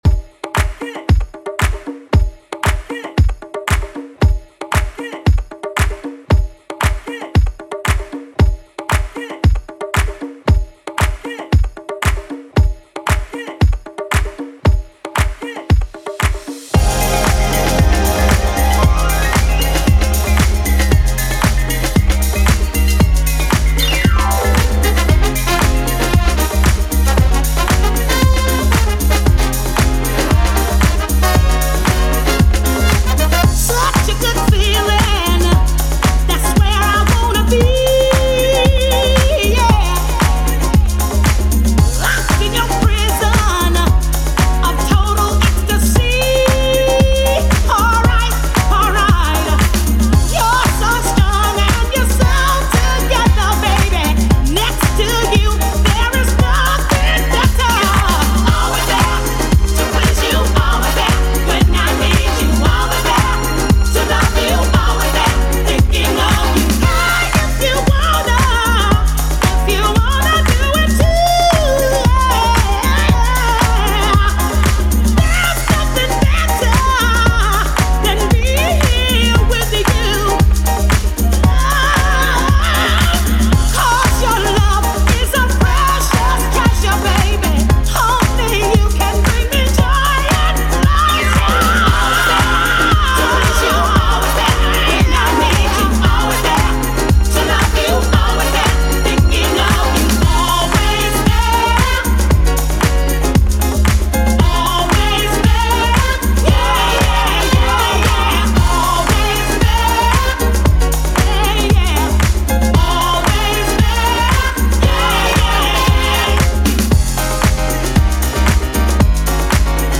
BPM: 115 Time